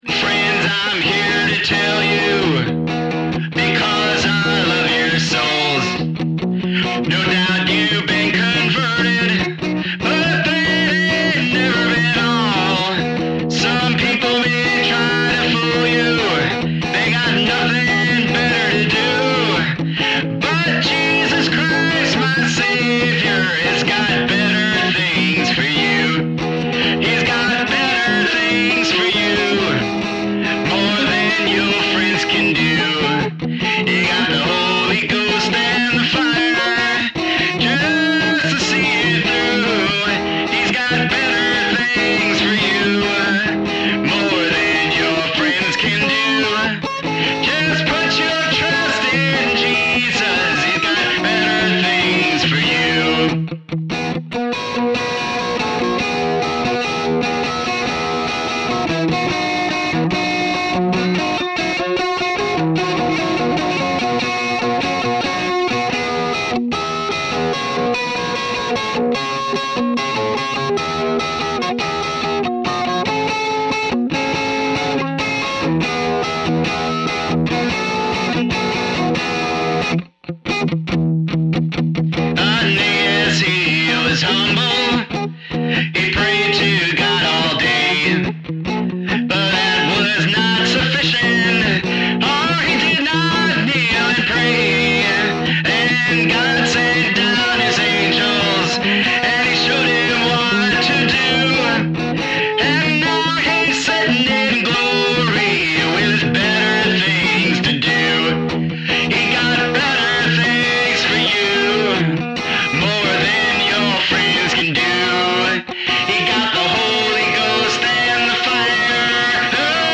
Here’s an attempt to use the Line6 TonePort UX2 for its real purpose, which is as a simulator of various classic amp sounds.
This is “Better Things for You” recorded with my Konablaster electric ukulele strung in mandolin tuning (and the whole thing tuned down a minor third, i.e., E-B-F#-C#, so as not to make the neck snap!) and then run through the GearBox software’s crunch guitar “American Punk” setting.
Then the vocal, such as it is, is also recorded through two crunch guitar settings: the first is “Blues Slide” and the second is “Street Fighting Man.”